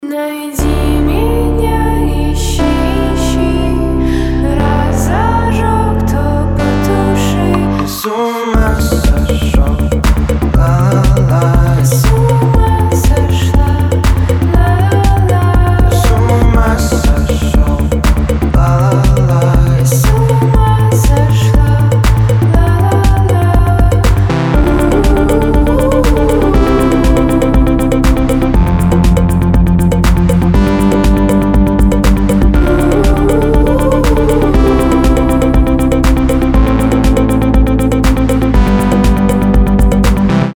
• Качество: 320, Stereo
атмосферные
дуэт
indie pop
alternative
Electropop
Инди-поп